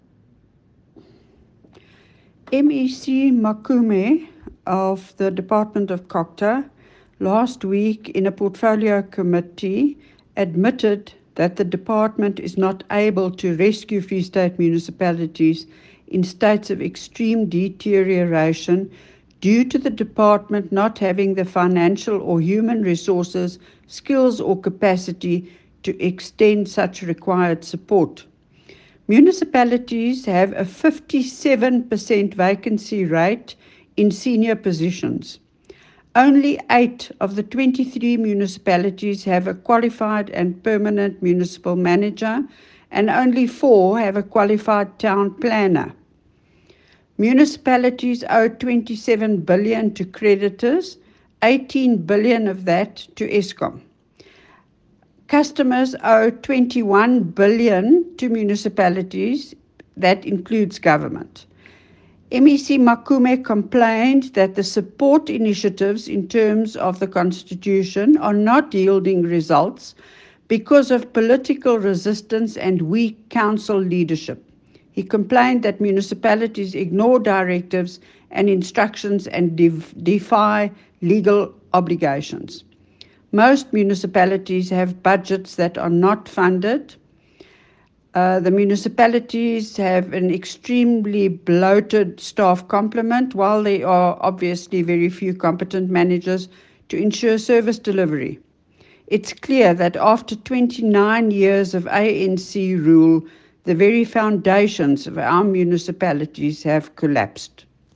Afrikaans soundbites by Leona Kleynhans MPL